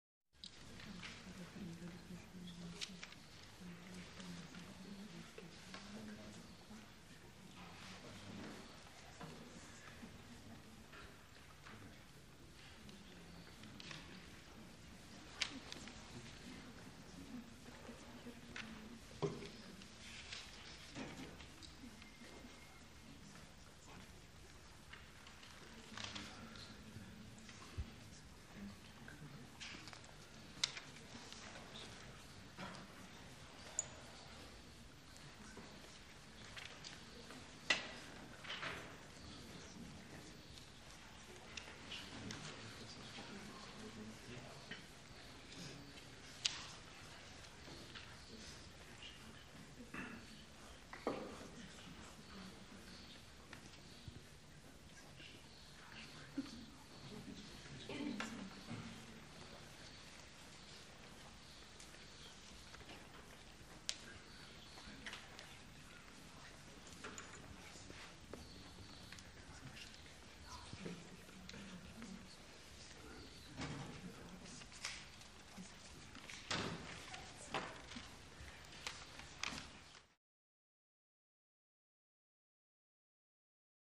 Room Tone - Very Quiet And Clean